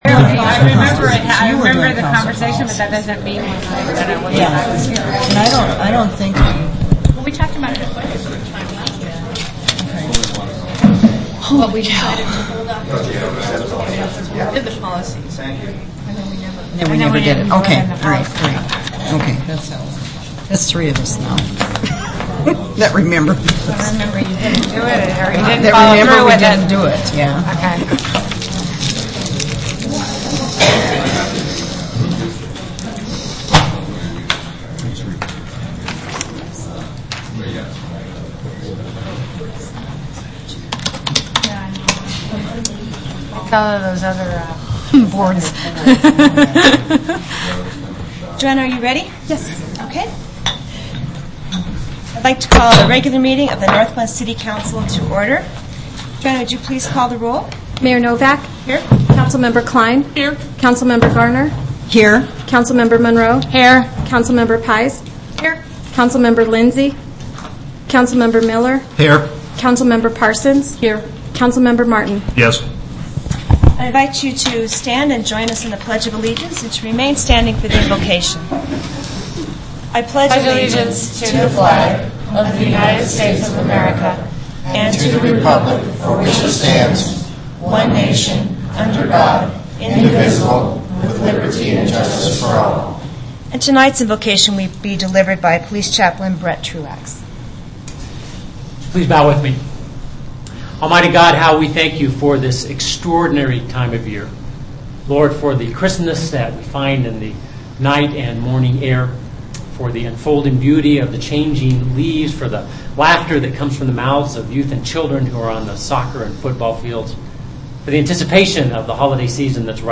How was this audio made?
Audio of City Council on 2006-09-14